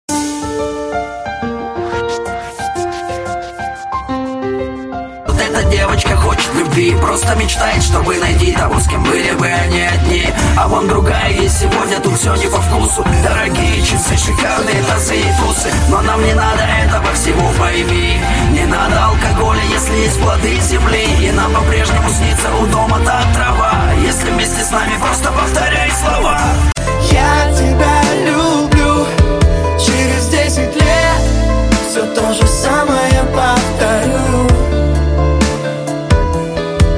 лирика
русский рэп
пианино
mash up